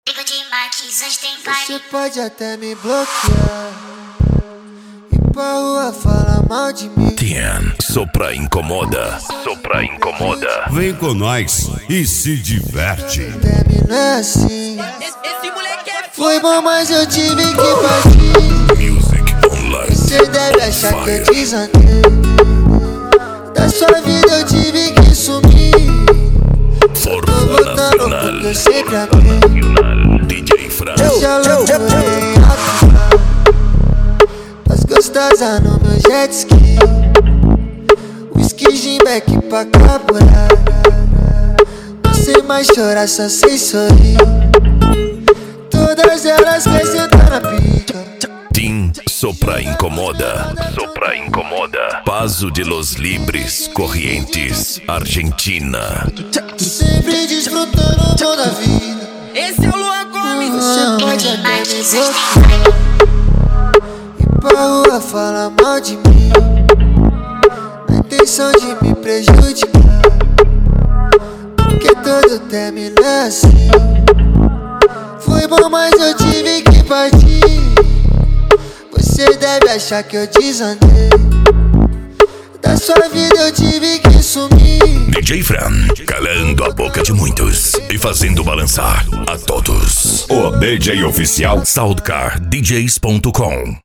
Remix
Funk
Bass